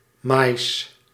Ääntäminen
RP : IPA : /kɔːn/ US : IPA : /kɔɹn/ CA : IPA : /kɔɹn/